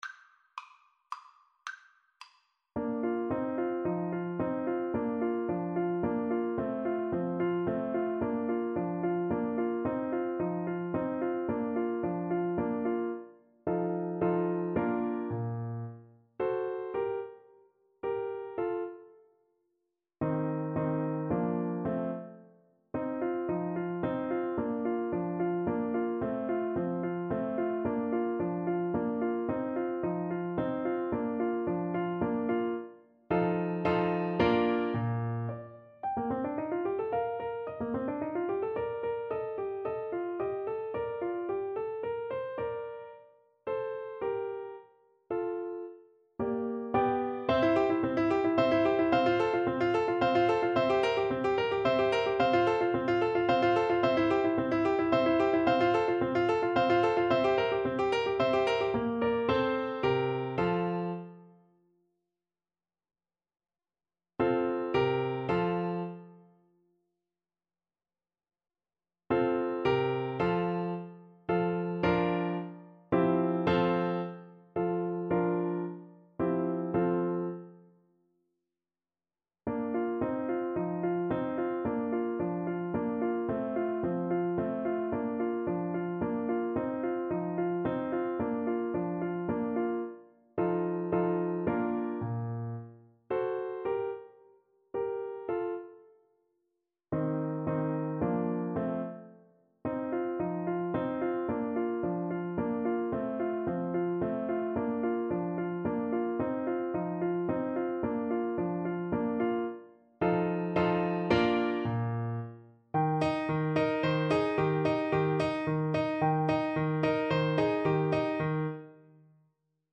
3/4 (View more 3/4 Music)
Classical (View more Classical Clarinet Music)